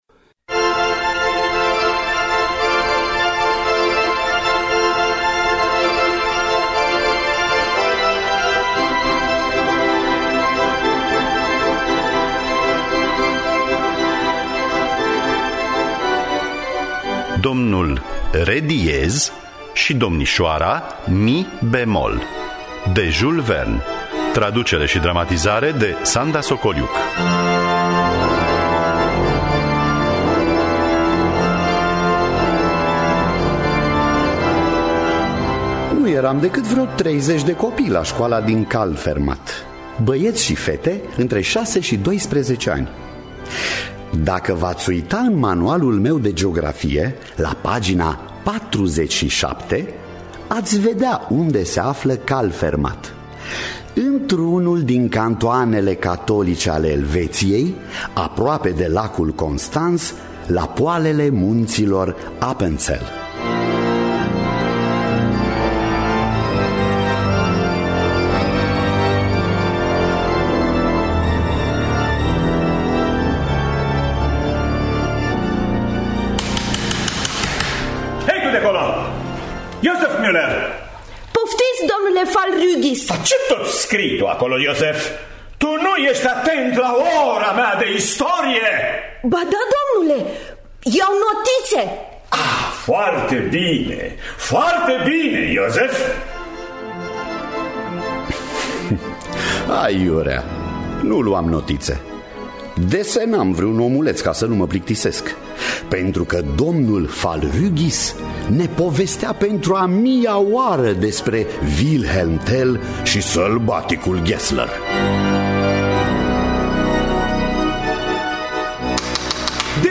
Traducere şi dramatizare radiofonică